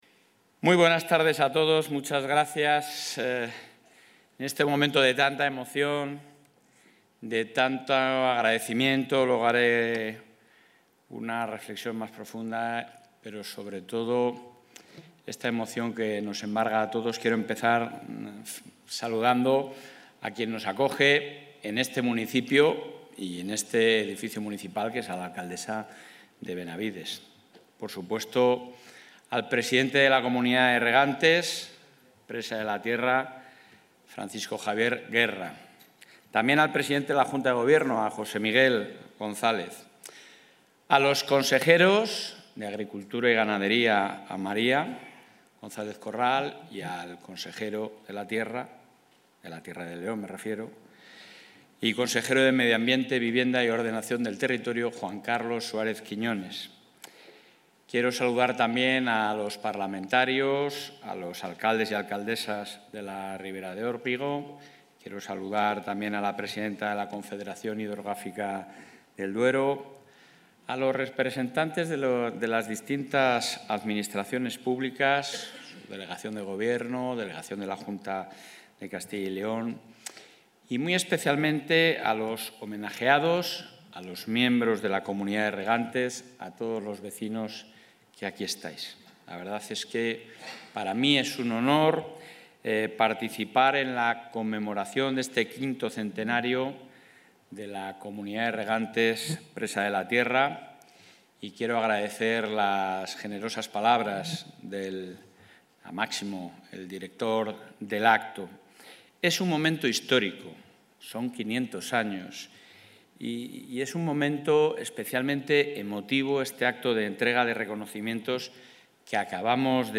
Intervención del presidente de la Junta.
El presidente de la Junta de Castilla y León, Alfonso Fernández Mañueco, ha participado, en el municipio leonés de Benavides de Órbigo, en los actos de conmemoración del V Centenario de la Presa de la Tierra, donde ha reafirmado el compromiso del Gobierno autonómico con el regadío, por contribuir a la generación de empleo y riqueza, el desarrollo rural y la mejora de la calidad de vida.